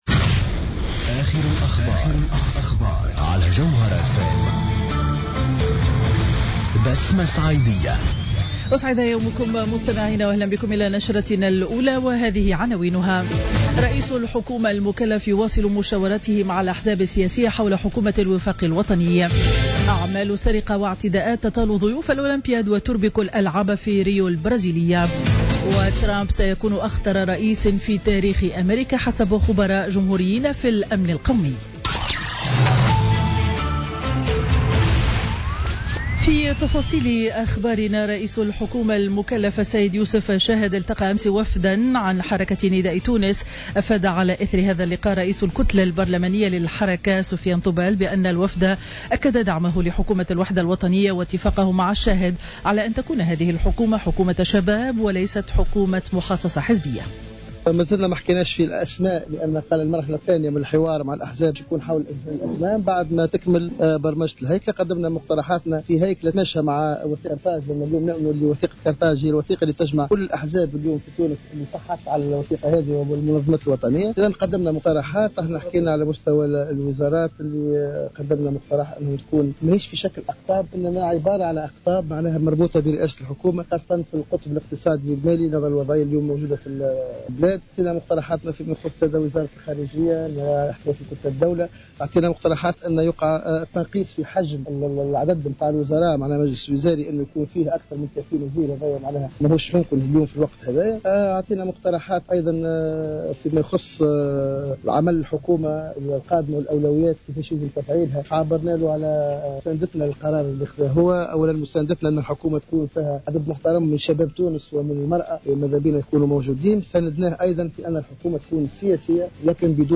Journal info 07h00 du mardi 9 août 2016